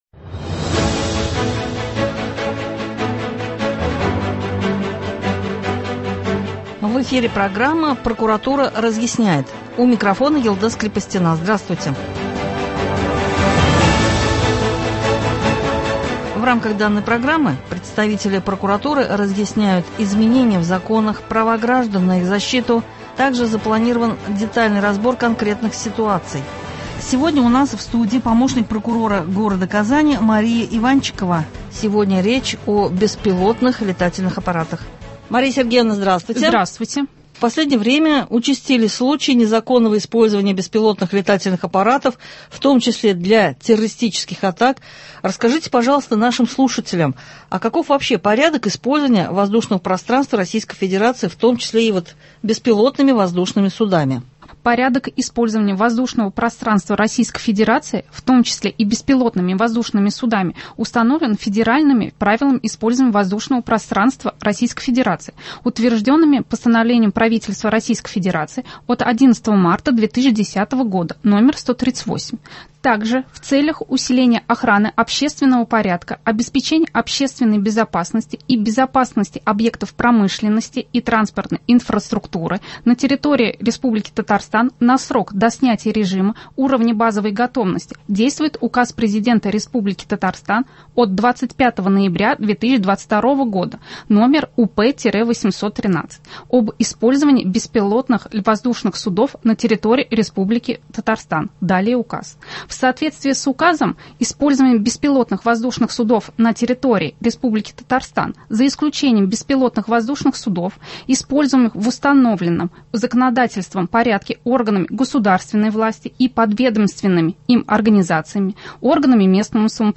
В рамках данной программы представители прокуратуры разъясняют : изменения в законах, права граждан на их защиту, также запланирован детальный разбор конкретных ситуаций. Сегодня у нас в студии